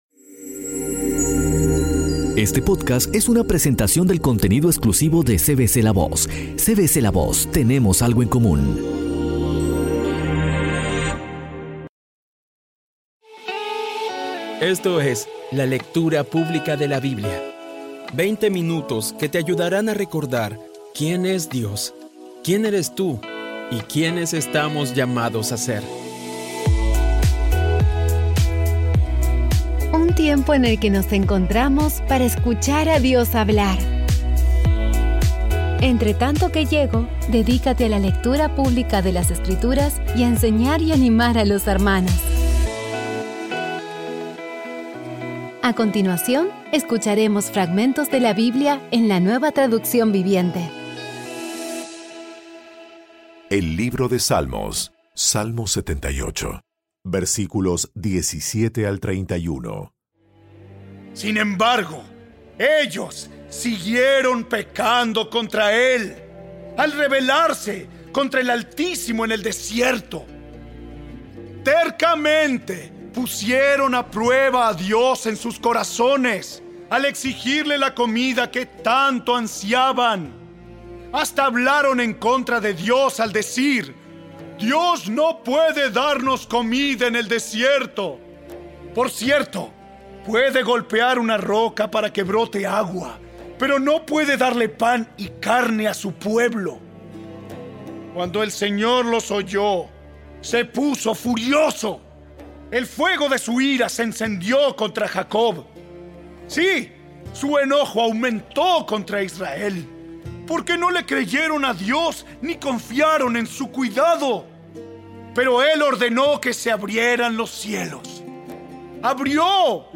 Audio Biblia Dramatizada Episodio 184
Poco a poco y con las maravillosas voces actuadas de los protagonistas vas degustando las palabras de esa guía que Dios nos dio.